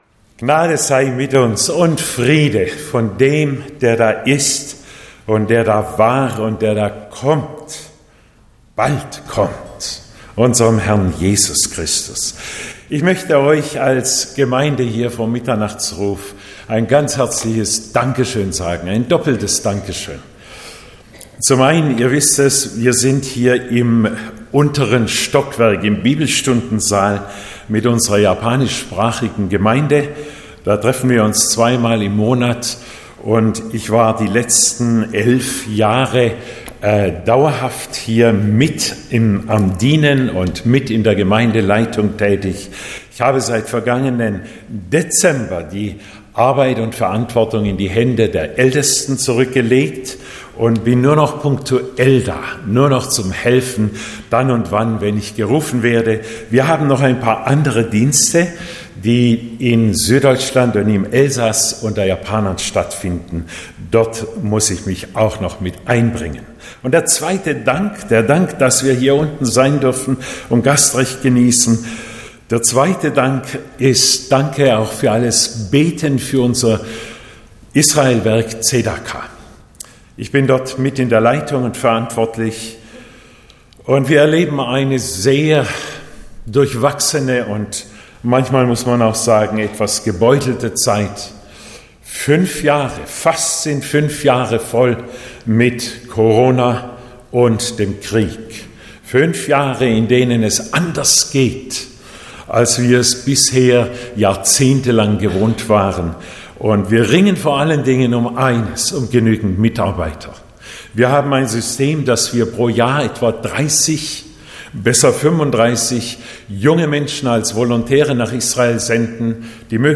Botschaft Zionshalle